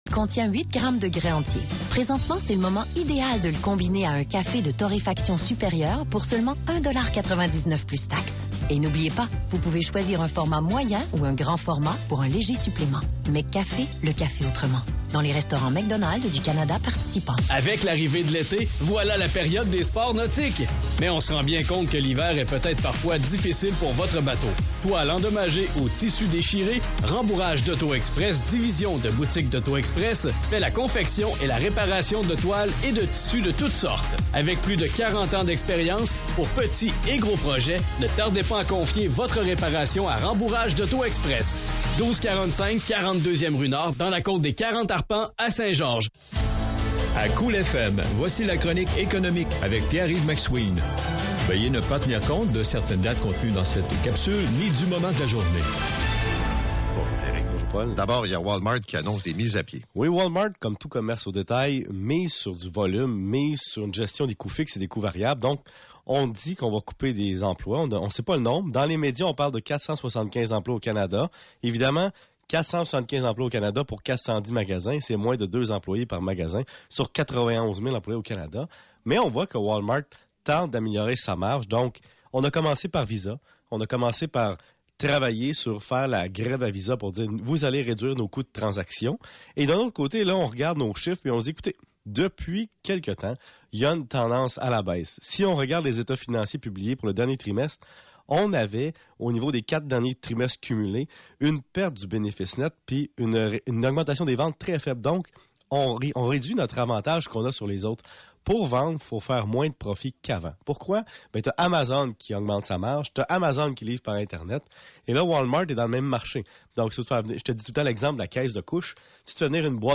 Pour ceux et celles qui ont manqué ou qui veulent réécouter notre chronique de l'emploi du 20 avril 2017 sur les ondes de Cool Fm 103.5